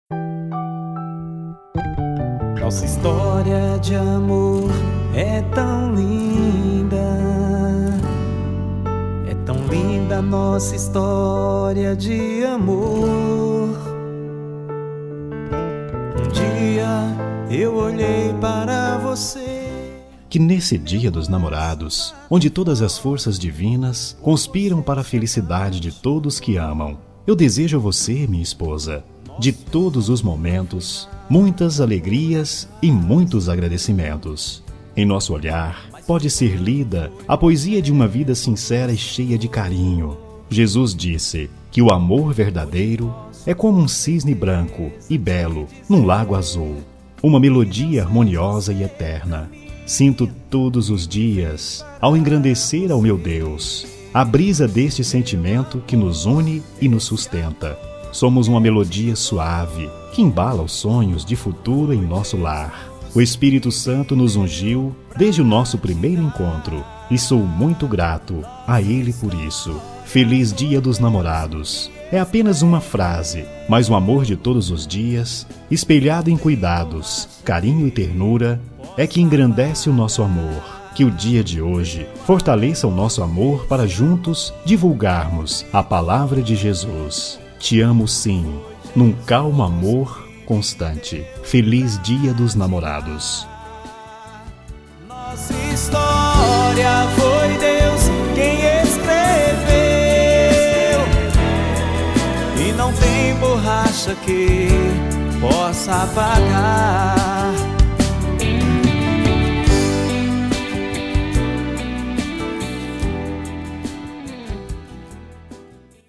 Voz Masculina
Código: 111111 – Música: Nacional Evangélica – Artista: Desconhecido
09-Esposa-Evangelica-masc-17-Musica-Romantica-evangelica-1.mp3